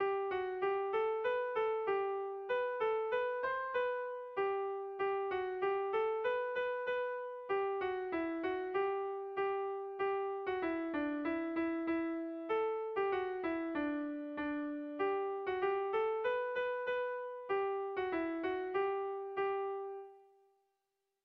Dantzakoa
Zortziko txikia (hg) / Lau puntuko txikia (ip)
A1A2BA2